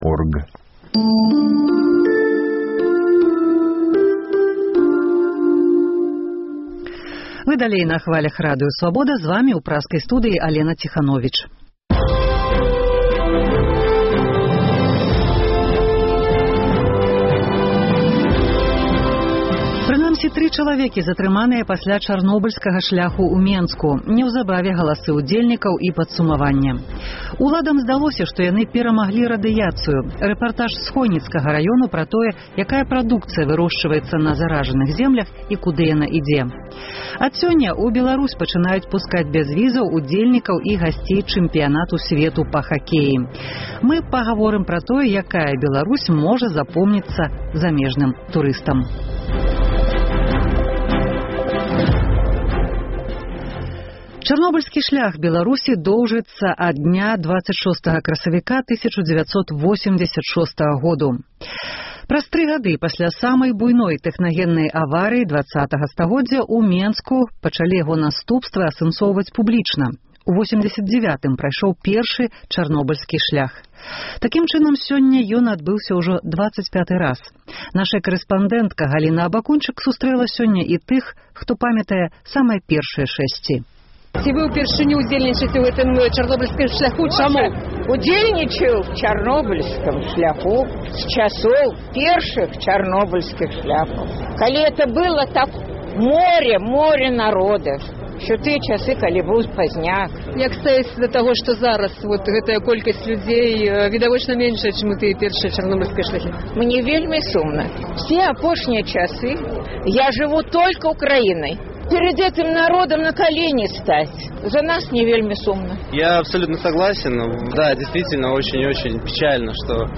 Адказваюць жыхары Берасьця.
Чарговую гадавіну самай буйной у гісторыі чалавецтва тэхнагеннай катастрофы дэмакратычная грамадзкасьць Беларусі адзначае традыцыйным "Чарнобыльскім шляхам". Жывое ўключэньне напачатку кожнай гадзіны, адразу пасьля міжнародных навінаў.
Рэпартаж з Хойніцкага раёну пра тое, якая прадукцыя вырошчваецца на заражаных землях і куды яна ідзе.